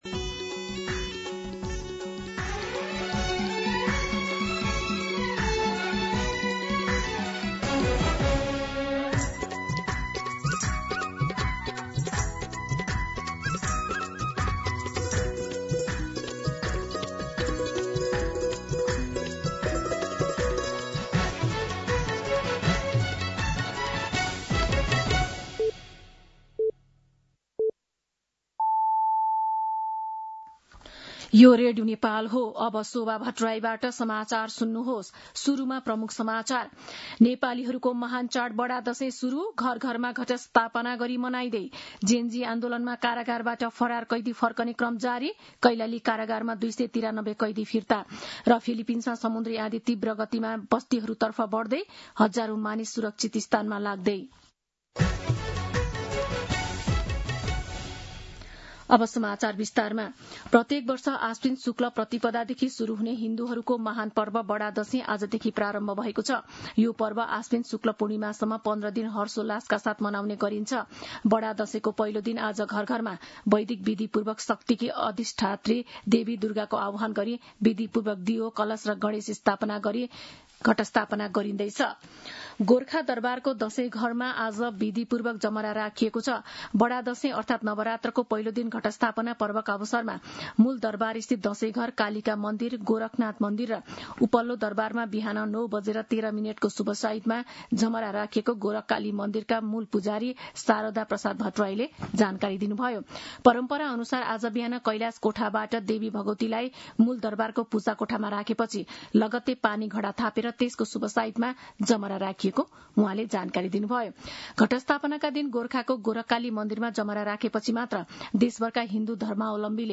दिउँसो ३ बजेको नेपाली समाचार : ६ असोज , २०८२
3pm-Nepali-News.mp3